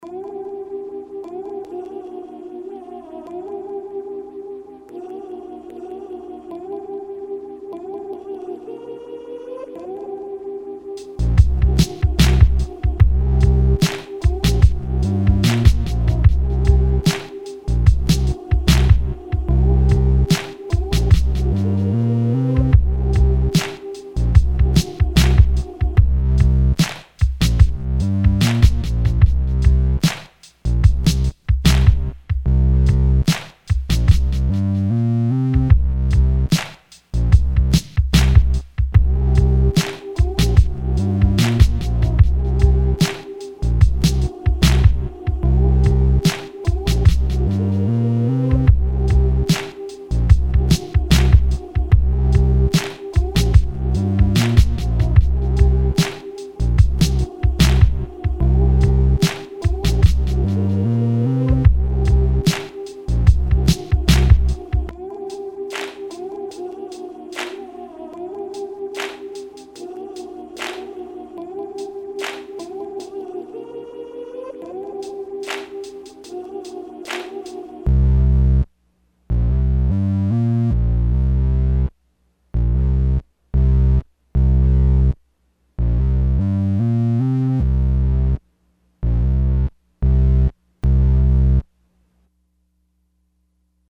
I just pieced it together, so there are some unwanted pops and the mix is not as smooth and tight as I would like it to be.
I experimented with some creamy side chain compression, which is almost working the way I'd like it to. I also experimented with slightly staggering the drums for "breath".
Filed under: Instrumental Information | Comments (6)
I made the kick drum trigger compression on the bass synths and the vocal sample.
The other tracks duck back a little in volume when the kick hits which I think can give the kick a more powerful feel, if that makes any sense.